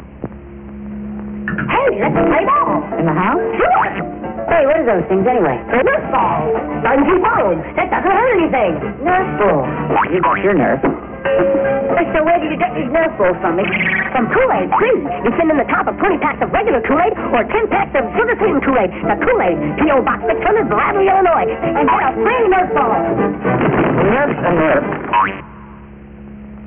Monkees Kool-Aid Commercials